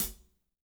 -16  HAT 5-R.wav